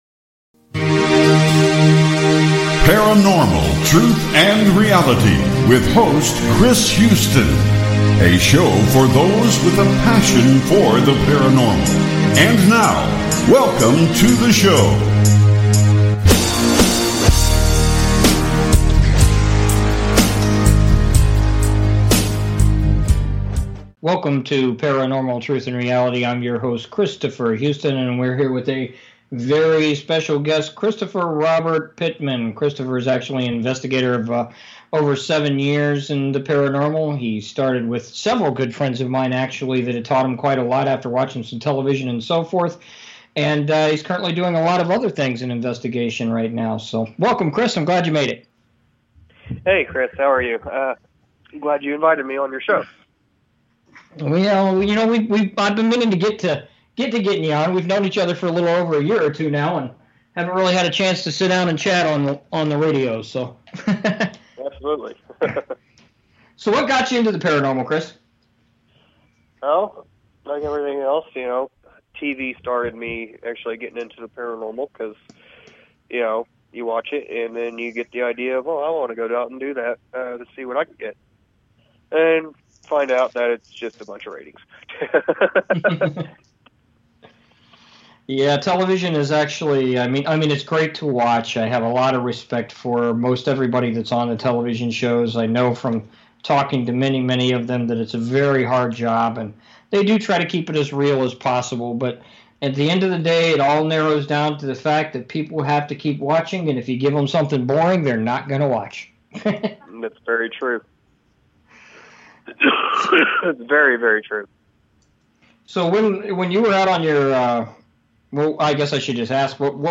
Paranormal Truth and Reality Show